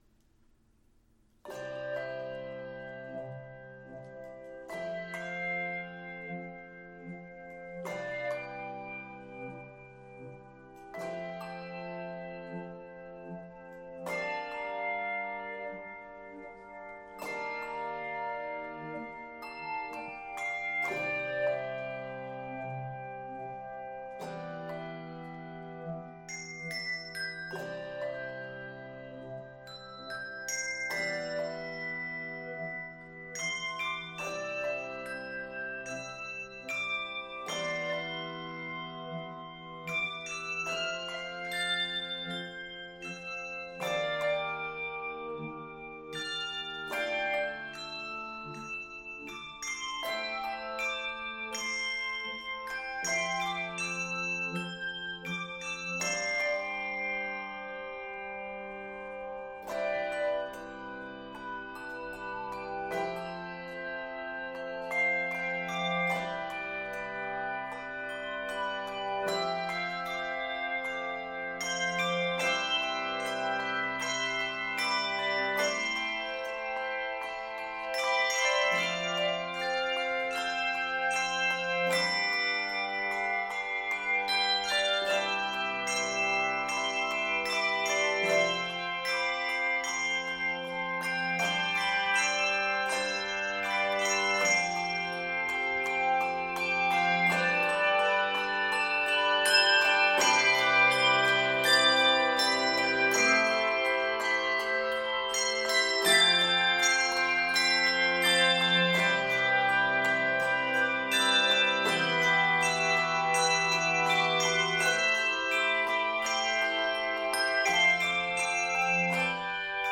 Starting softly with echoes
Keys of C Major, D Major, and E Major.